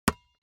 جلوه های صوتی
دانلود صدای تصادف 49 از ساعد نیوز با لینک مستقیم و کیفیت بالا